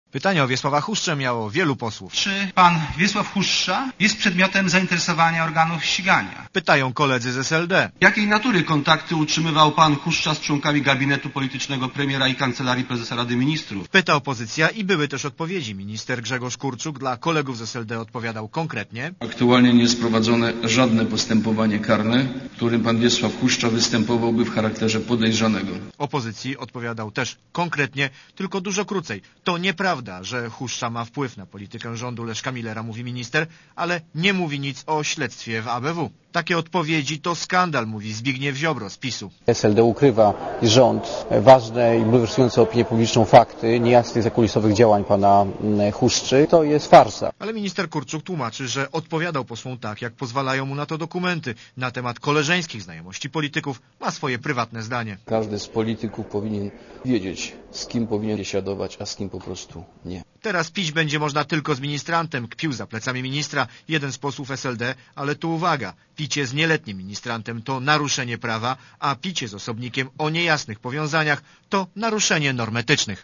Relacja reportera Radia Zet (288Kb)